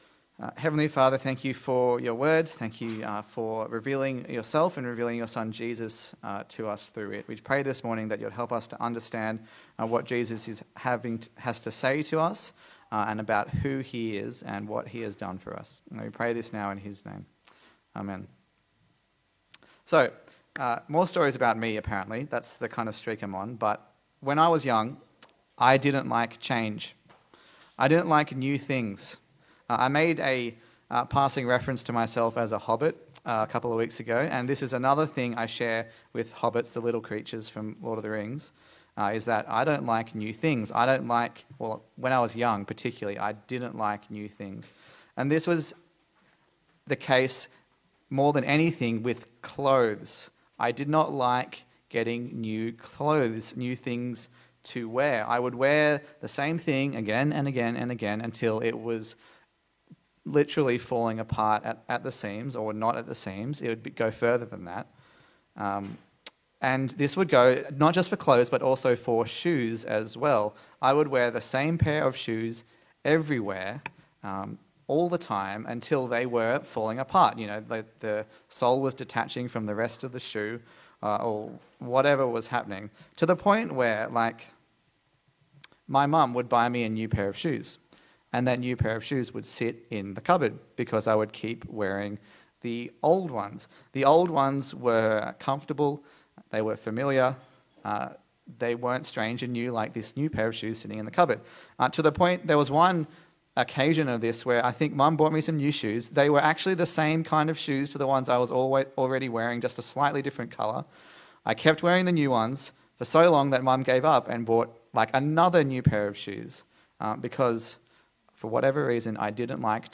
Luke Passage: Luke 5:33-6:16 Service Type: Sunday Service